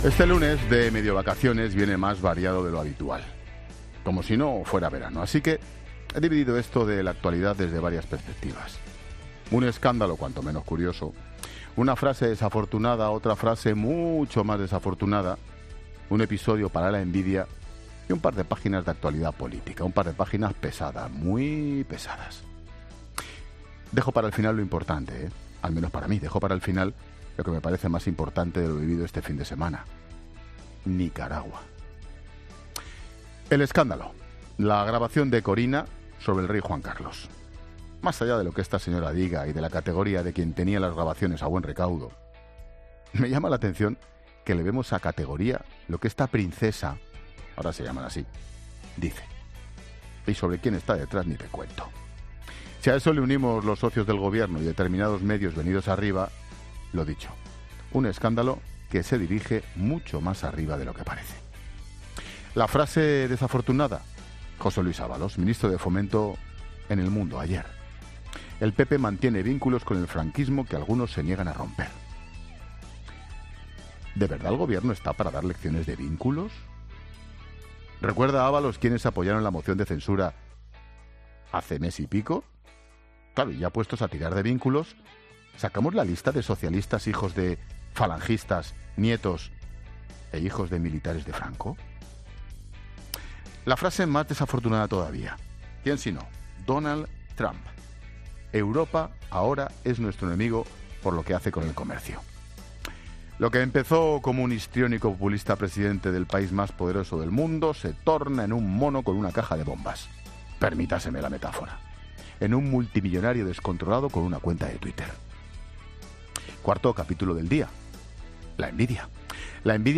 Escucha ya el monólogo de las 16 horas de Ángel Expósito